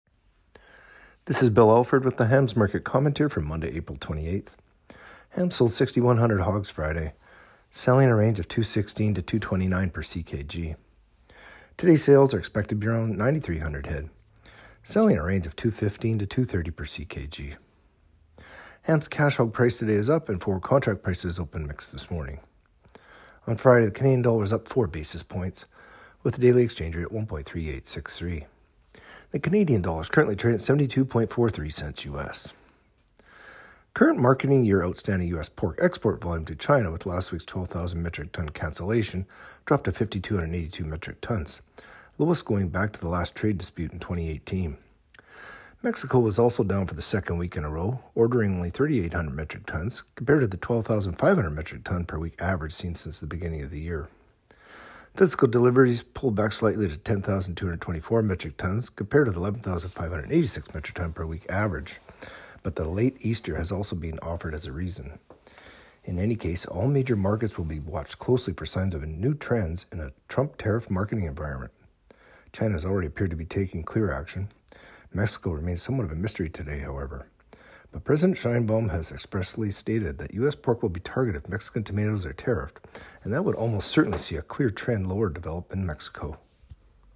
Market-Commentary-Apr.-29-25.mp3